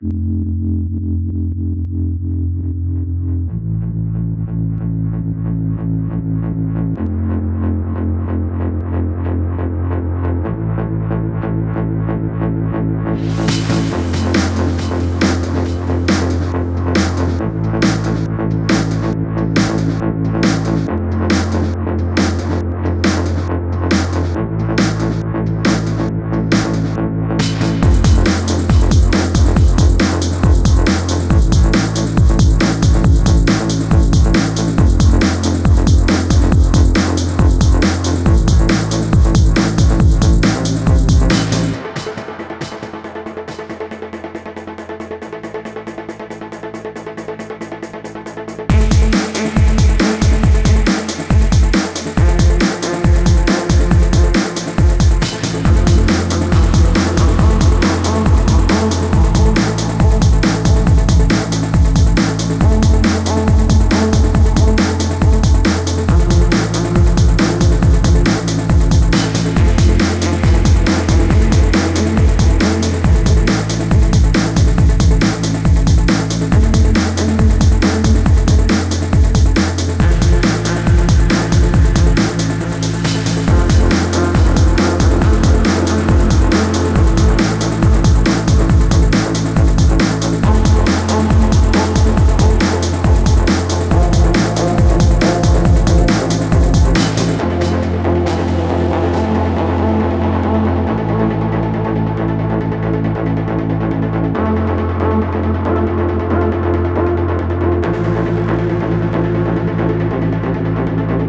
Осеннее настроение.